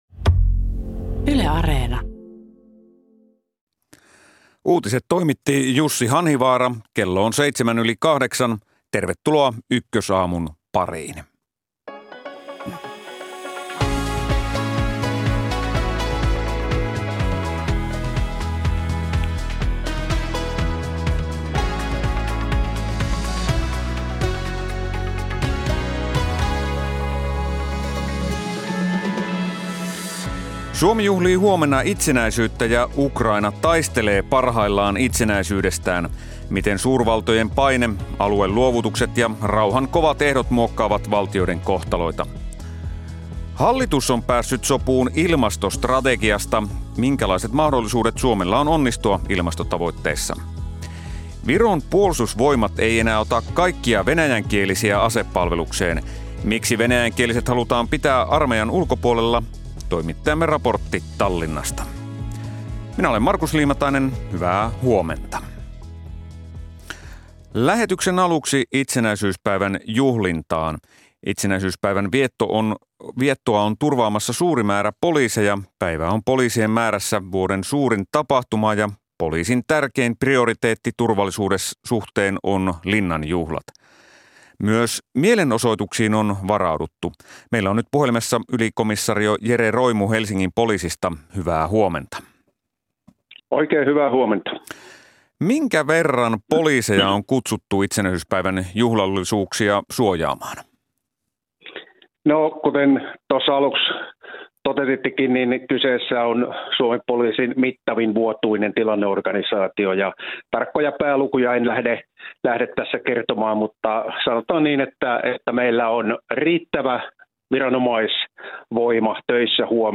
Ajankohtaista politiikkaa ja taloutta Suomessa ja maailmalla. Eturivin haastateltavat ja terävimmät kysymykset.